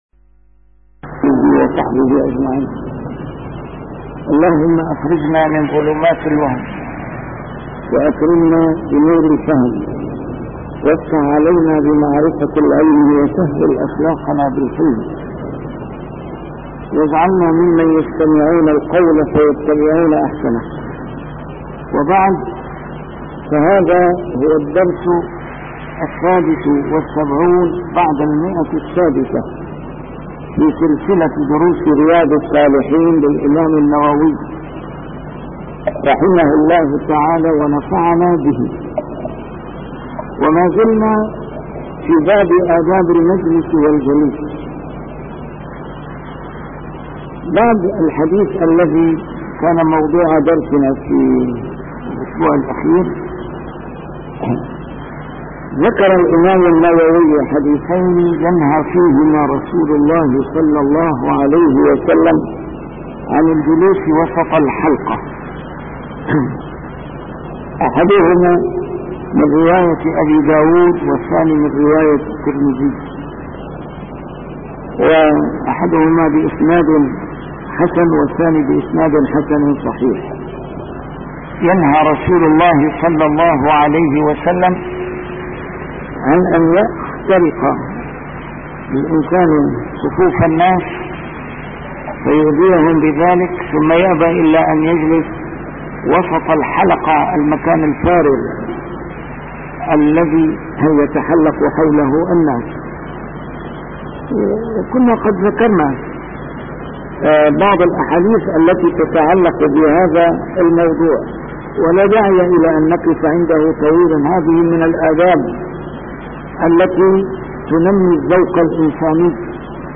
A MARTYR SCHOLAR: IMAM MUHAMMAD SAEED RAMADAN AL-BOUTI - الدروس العلمية - شرح كتاب رياض الصالحين - 676- شرح رياض الصالحين: آداب المجلس والجليس